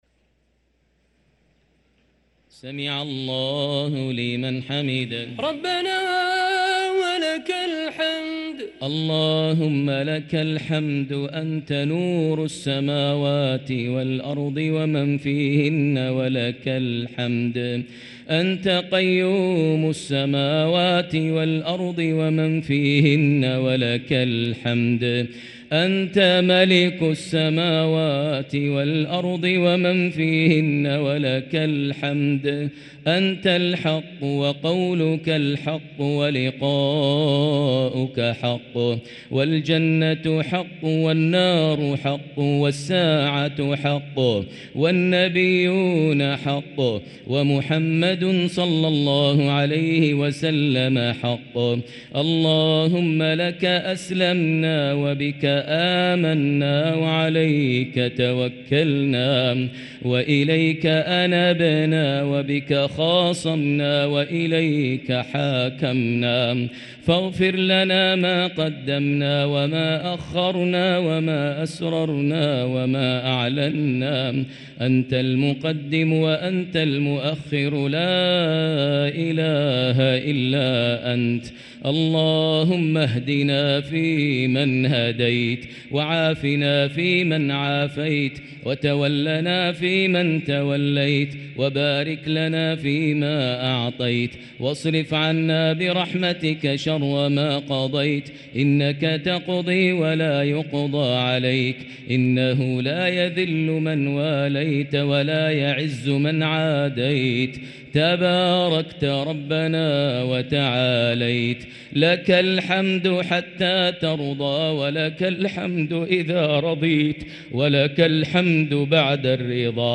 دعاء القنوت ليلة 28 رمضان 1444هـ > تراويح 1444هـ > التراويح - تلاوات ماهر المعيقلي